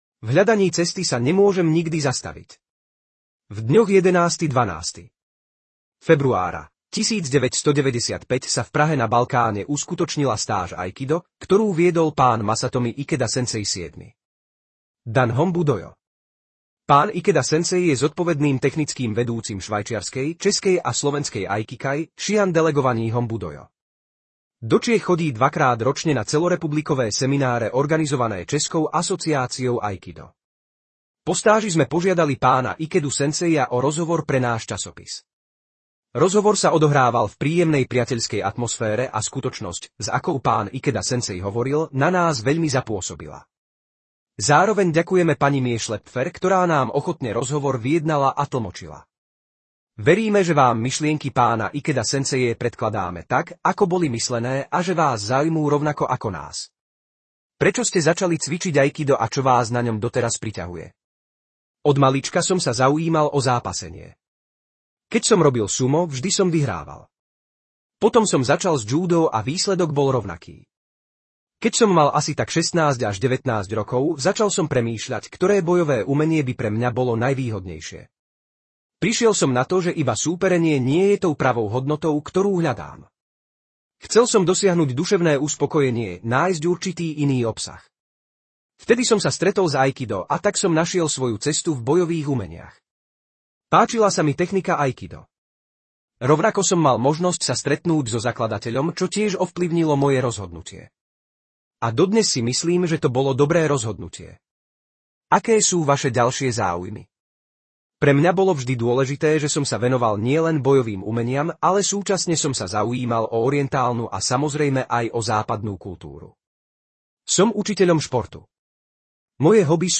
Rozhovor: časopis Aikido 1995